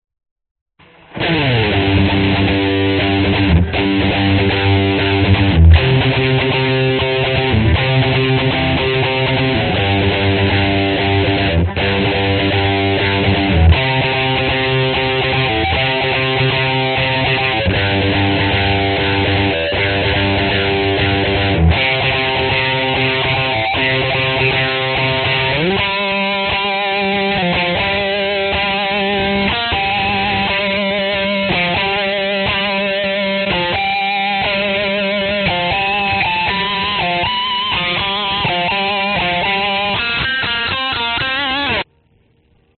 道歉的吉他油炸
Tag: deep_fried 吉他 器乐 循环 摇滚 c_minor 电动 失真